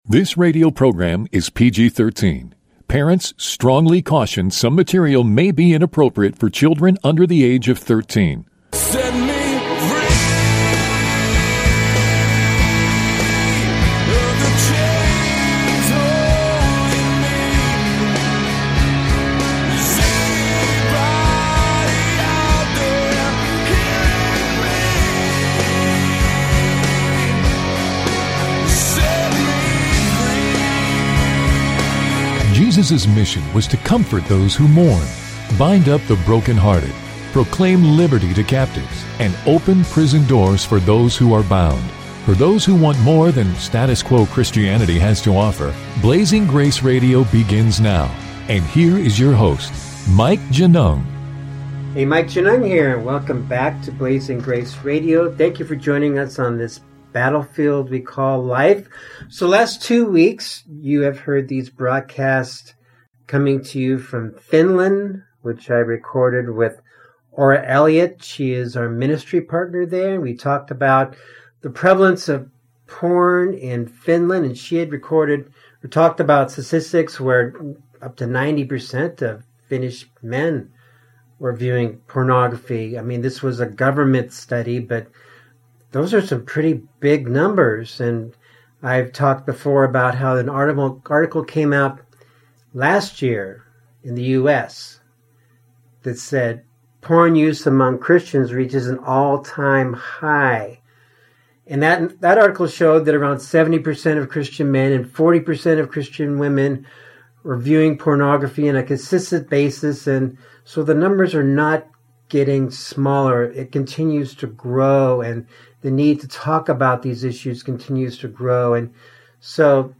This show was recorded in Modena, Italy.